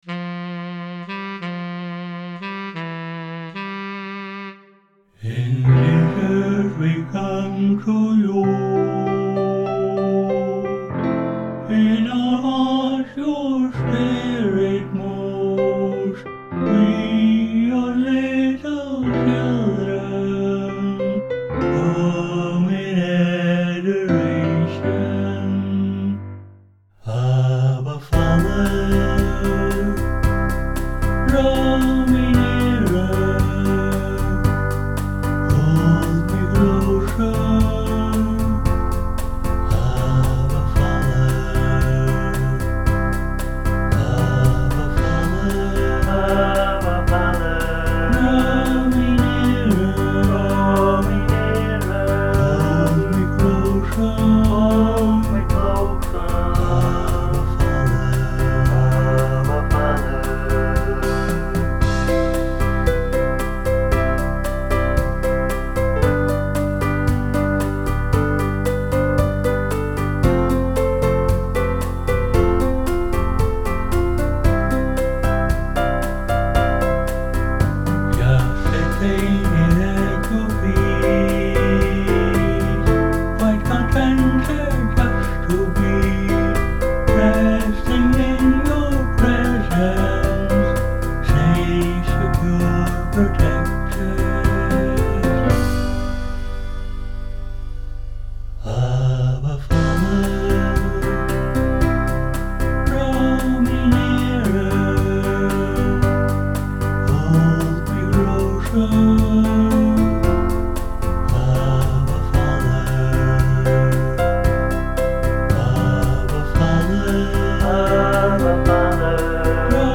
a spiritual intimacy song